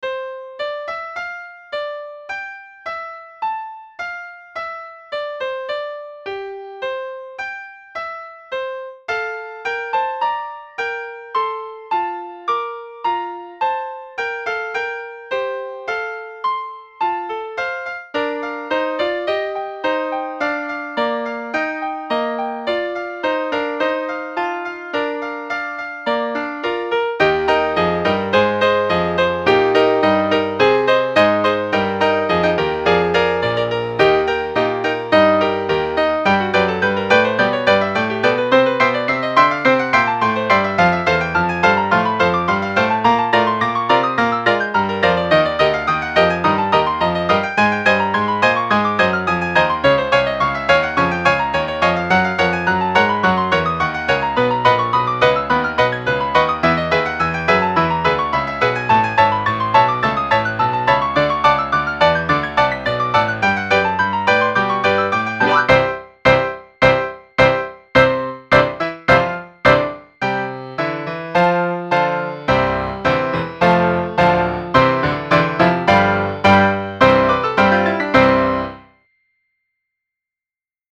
I am reposting some of my early songs with stereo effects. Listen as each piano comes in at a different place "in your head"